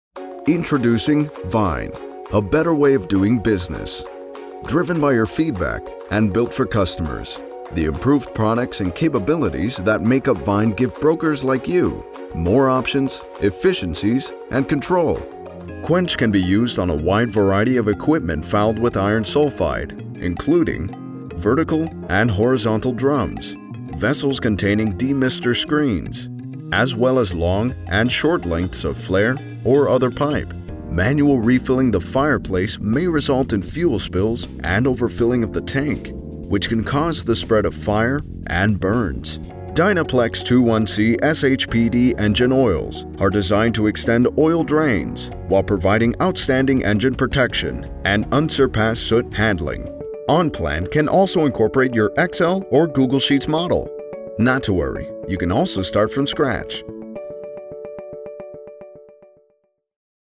Male
Corporate
Words that describe my voice are Warm, Deep, Natural.